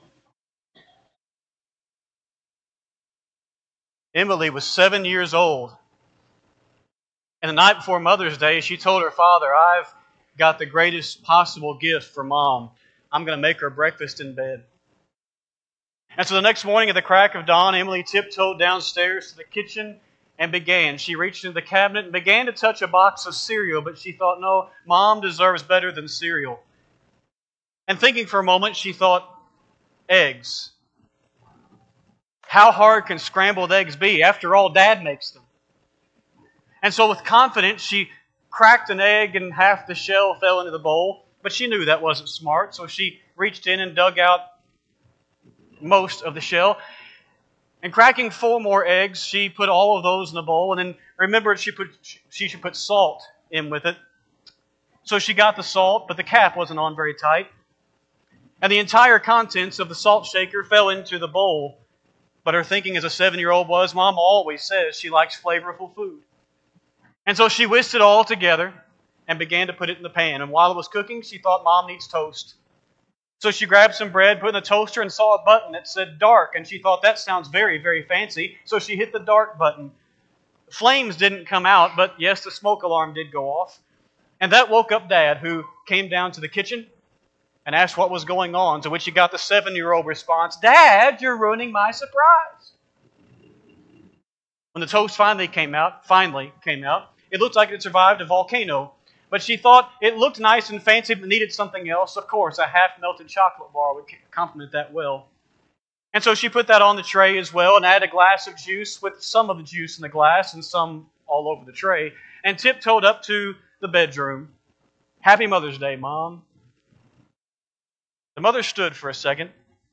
Sunday AM Sermon
5-11-25-Sunday-AM-Sermon.mp3